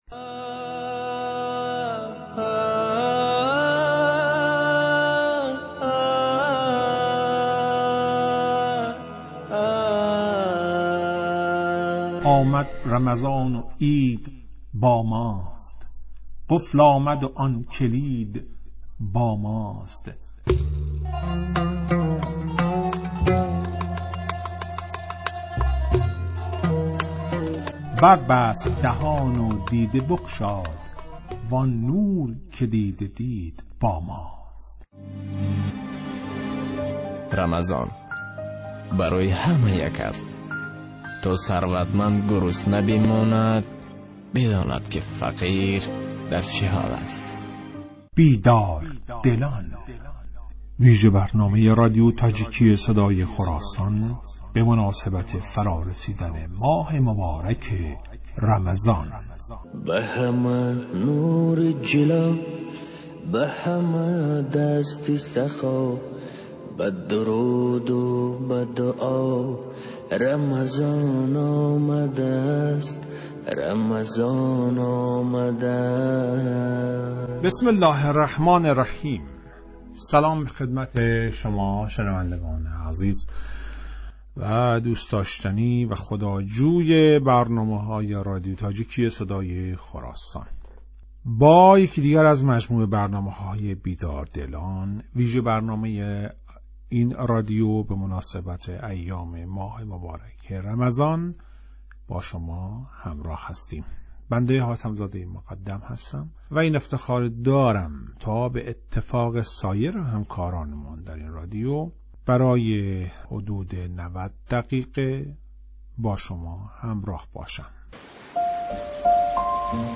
"بیدار دلان" ویژه برنامه ای است که به مناسبت ایام ماه مبارک رمضان در رادیو تاجیکی تهیه و پخش می شود.